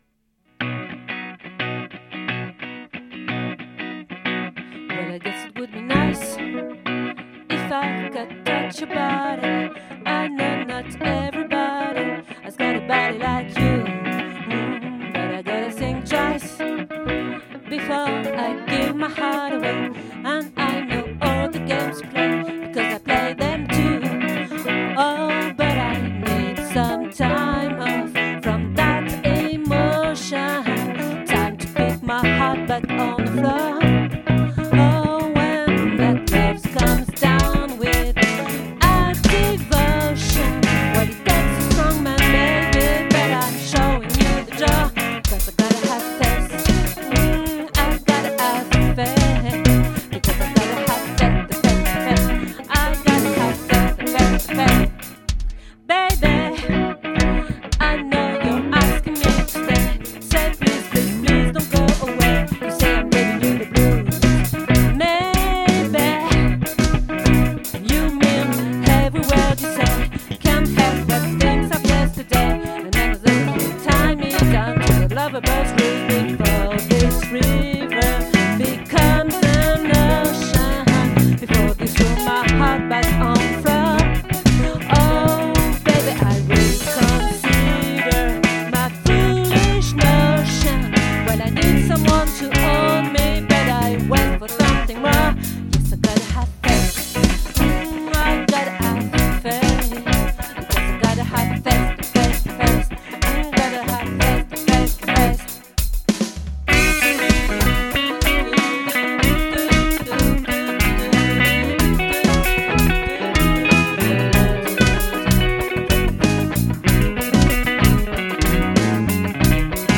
🏠 Accueil Repetitions Records_2022_10_12